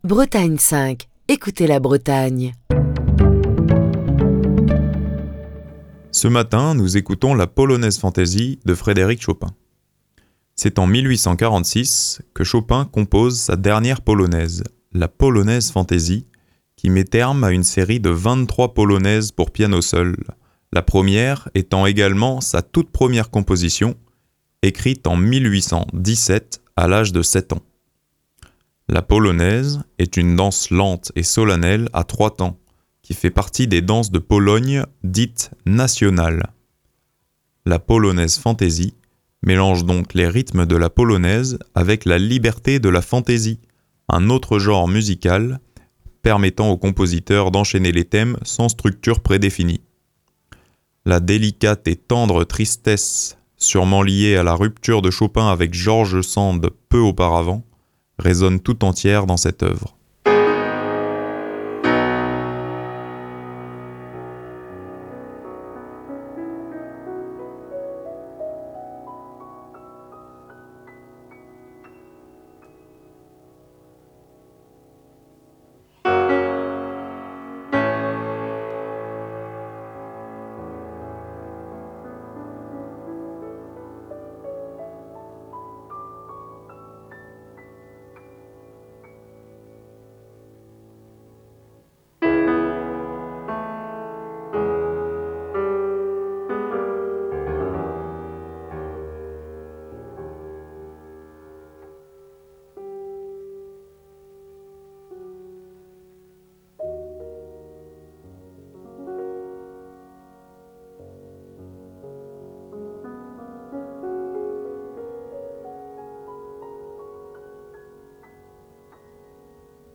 pour piano seul
La polonaise est une danse lente et solennelle à trois temps, qui fait partie des danses de Pologne dites « nationales ». La Polonaise Fantaisie mélange donc les rythmes de la Polonaise avec la liberté de la Fantaisie, un autre genre musical, permettant au compositeur d’enchaîner les thèmes sans structure prédéfinies. La délicate et tendre tristesse, surement lié à la rupture de Chopin avec George Sand peu auparavant, résonne tout entière dans cette œuvre. La Polonaise Fantaisie, que je vous propose ce matin est jouée par l’immense Mauricio Pollini, à qui je voulais rendre hommage puisqu’il nous a quitté le 23 mars dernier, à l’âge de 82 ans.